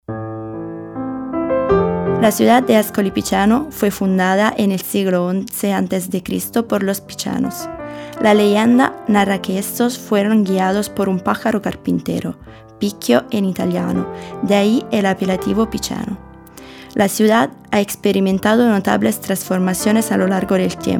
AUDIOGUIDA E SISTEMA MULTILINGUE